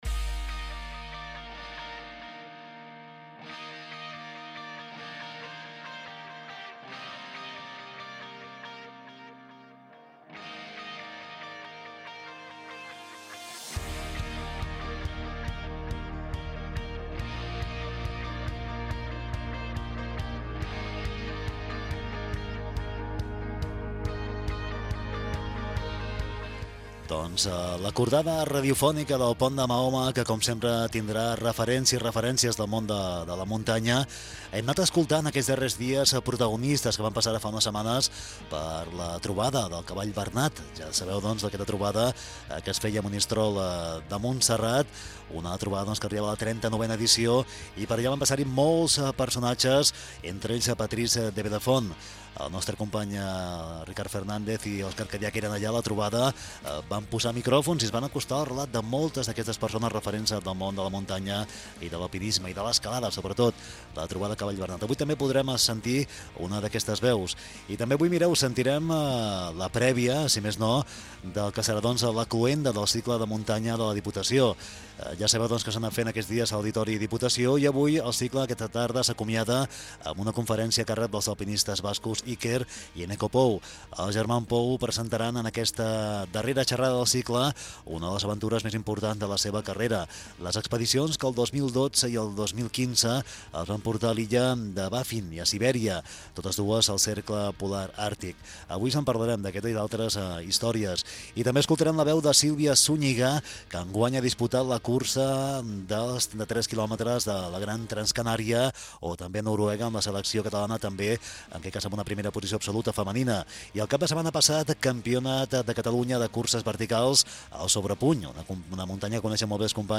El pont de Mahoma Gènere radiofònic Esportiu